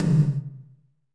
Index of /90_sSampleCDs/Northstar - Drumscapes Roland/DRM_Techno Rock/TOM_F_T Toms x
TOM F T H0ML.wav